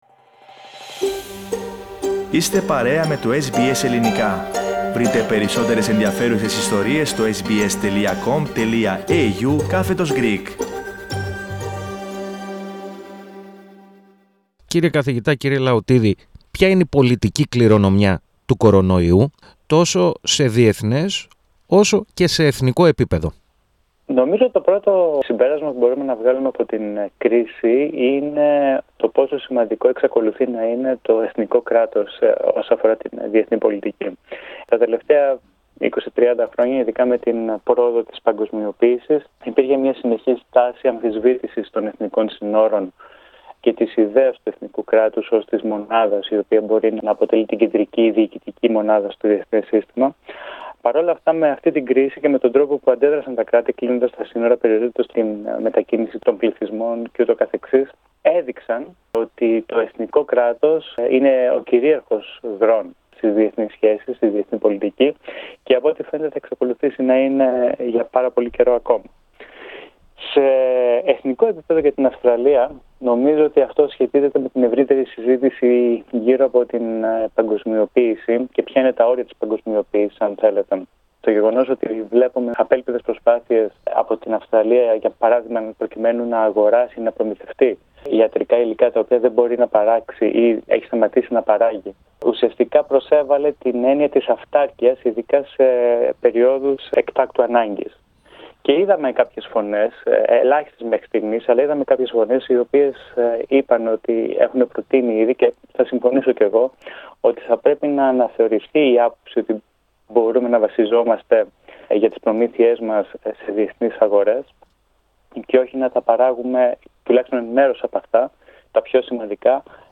Ποια είναι η πολιτική κληρονομιά της πανδημίας του κορωνοϊού, τόσο σε εθνικό όσο και σε διεθνές επίπεδο. Στο ερώτημα απαντά ο καθηγητής Διεθνών Σχέσεων